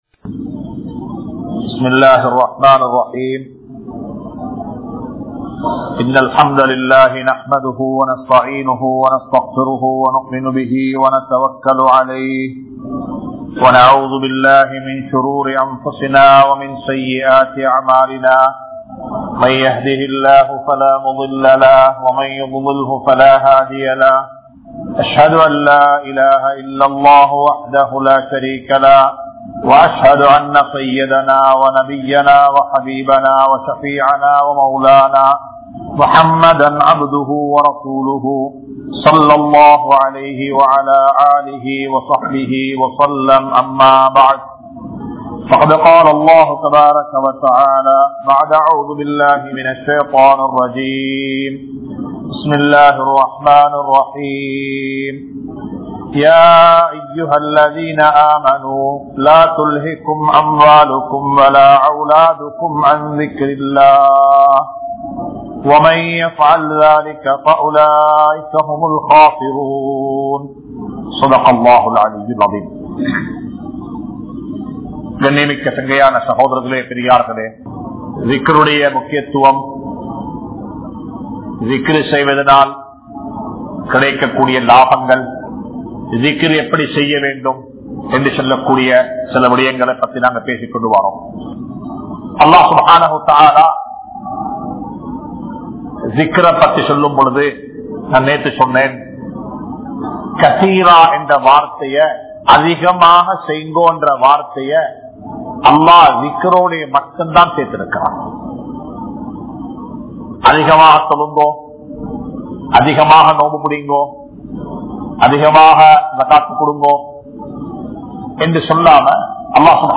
Allah`vai Ninaivu Kooruvathin Sirppuhal (அல்லாஹ்வை நினைவு கூறுவதின் சிறப்புகள்) | Audio Bayans | All Ceylon Muslim Youth Community | Addalaichenai
Dehiwela, Muhideen (Markaz) Jumua Masjith